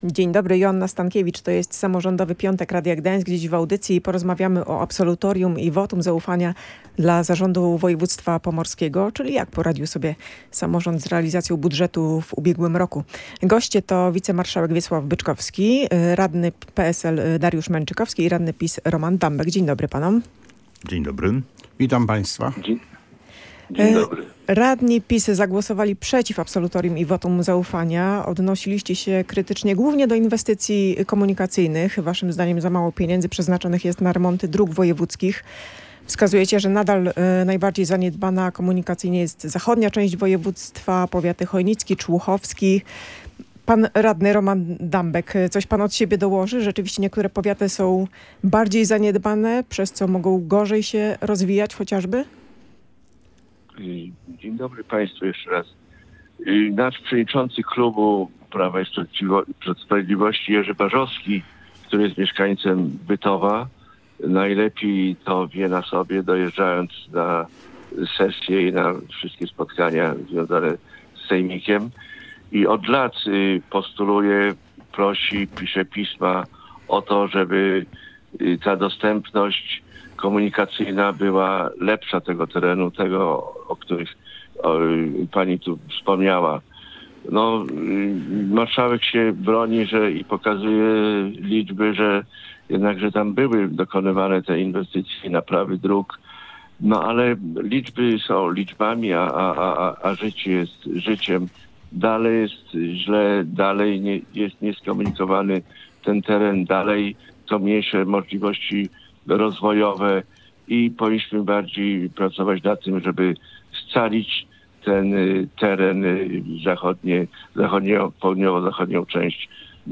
W audycji „Samorządowy Piątek” rozmawialiśmy o absolutorium i wotum zaufania dla zarządu województwa. Jak poradził sobie samorząd z realizacją budżetu i zapowiadanych inwestycji w ubiegłym roku?